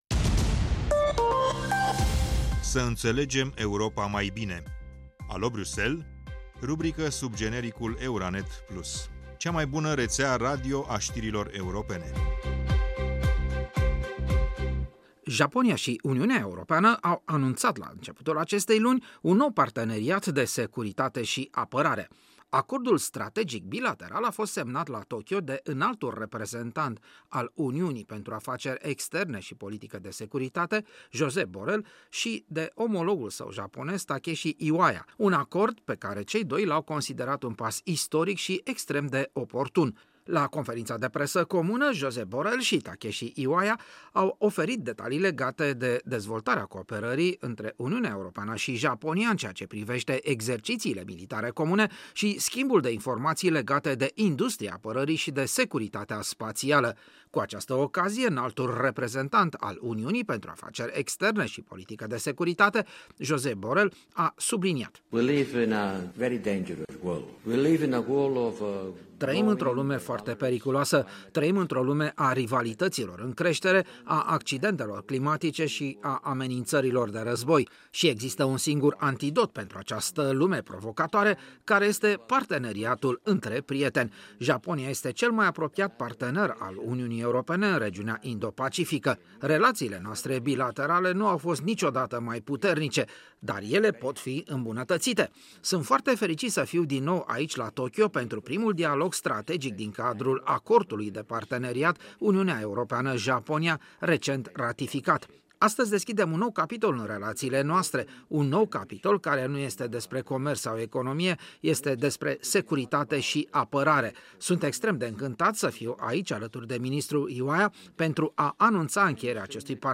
La conferința de presă comună, Josep Borell și Takeshi Iwaya au oferit detalii legate de dezvoltarea cooperării între Uniunea Europeană și Japonia în ceea ce privește exercițiile militare comune și schimbul de informații legate de industria apărării și de securitatea spațială.